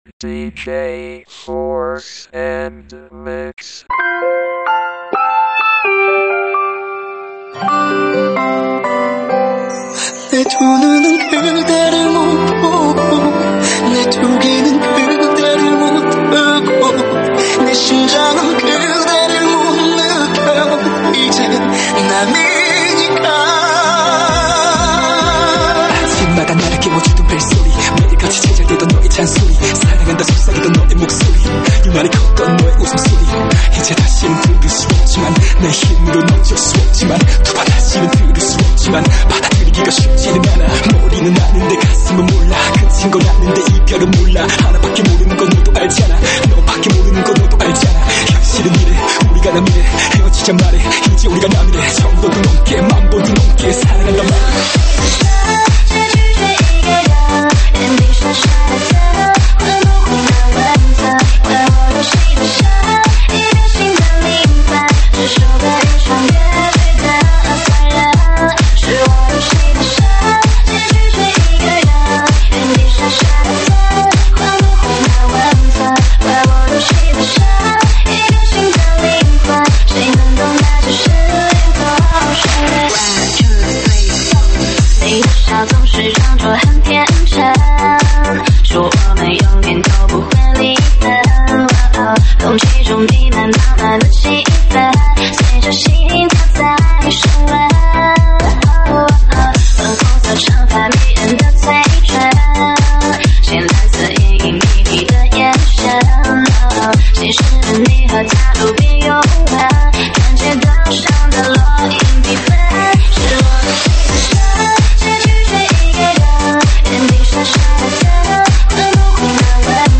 栏目：慢摇舞曲